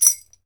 Index of /90_sSampleCDs/E-MU Producer Series Vol. 7 – Old World Instruments (CD 2)/Drums&Percussion/Bell Rattle
BEL RATTL03L.wav